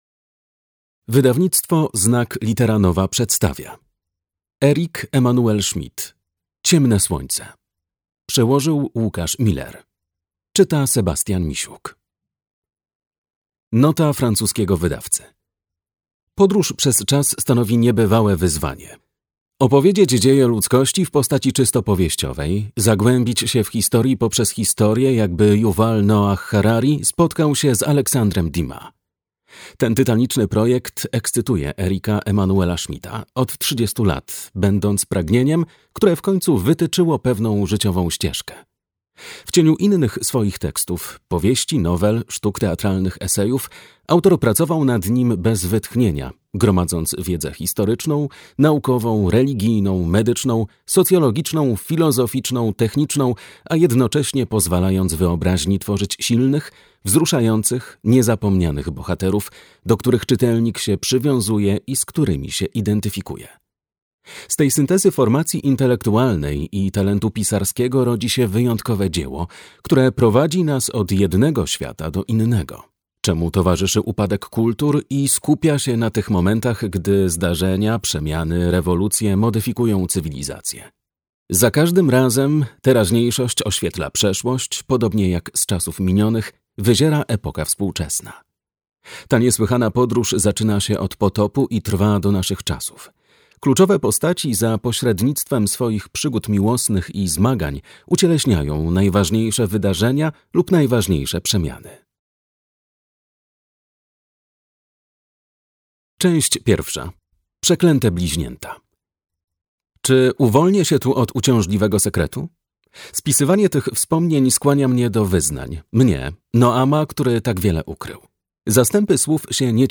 Ciemne słońce - Eric-Emmanuel Schmitt - audiobook